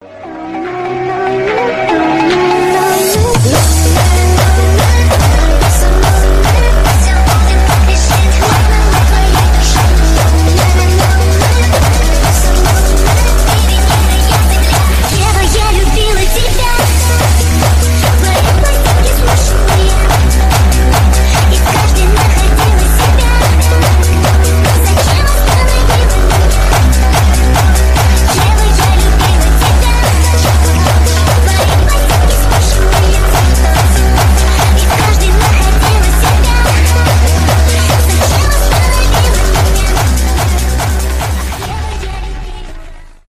мощные басы